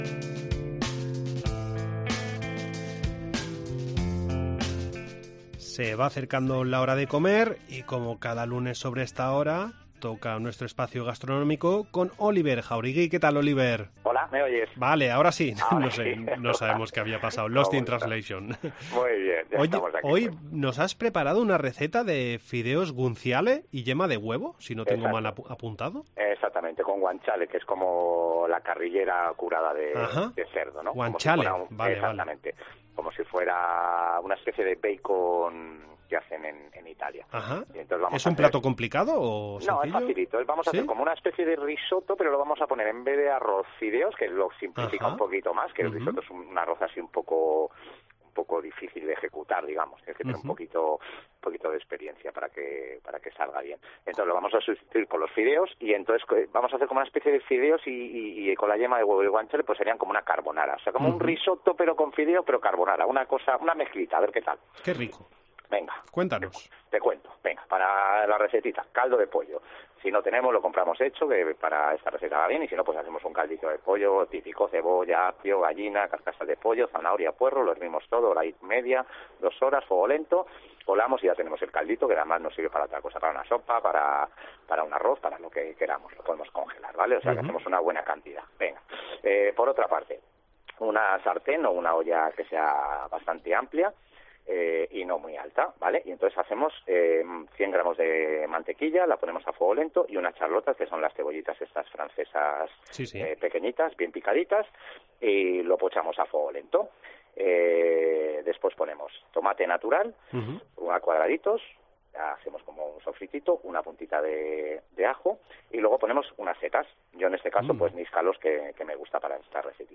Entrevista en La Mañana en COPE Más Mallorca, lunes 12 de diciembre de 2022.